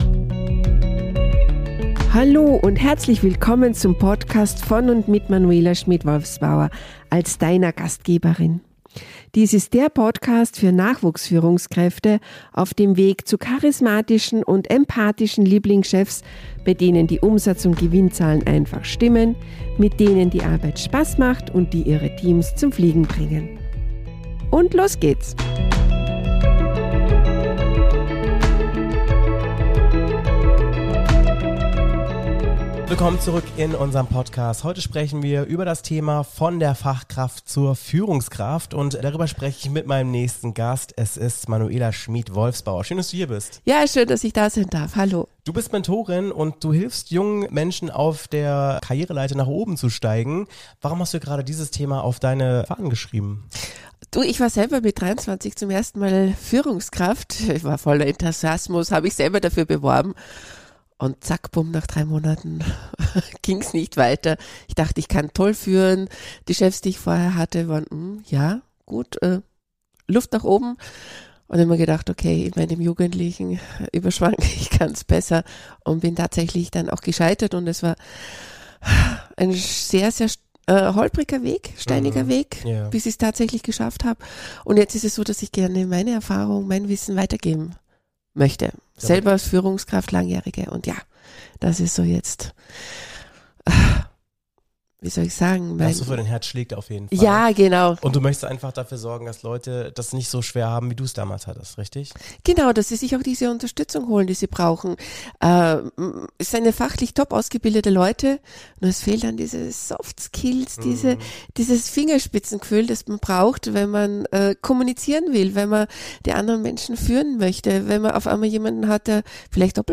im Gespräch über Führungsverantwortung, Selbstführung und Soft Skills